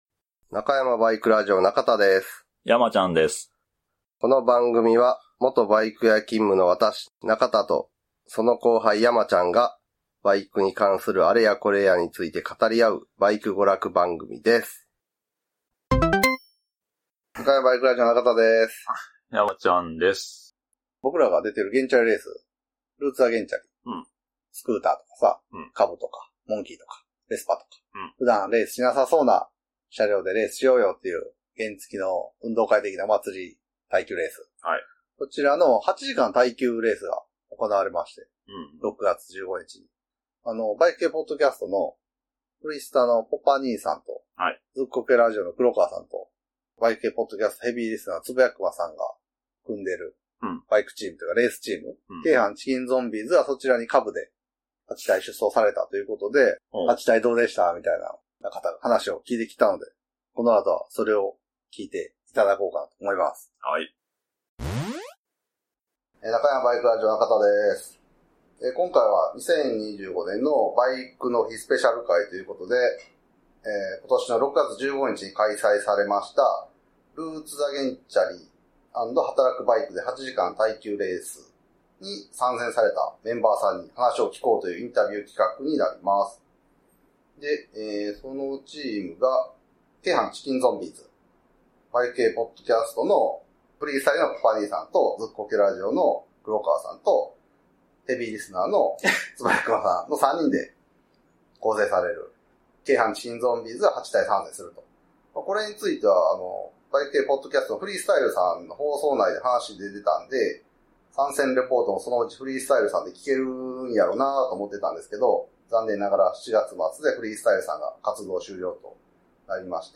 ＜＜ご注意＞＞ 独断と偏見に基づいて会話しておりますので、正統派ライダーの方は気分を害する恐れがあります。